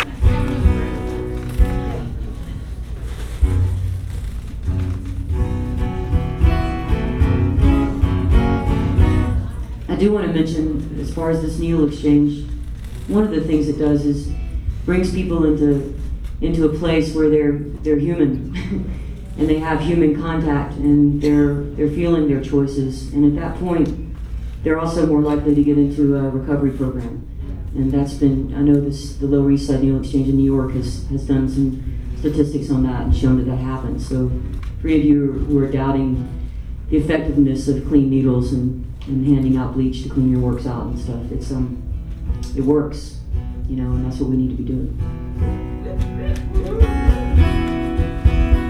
lifeblood: bootlegs: 2003-08-24: red light cafe - atlanta, georgia (atlanta harm reduction center benefit) (amy ray)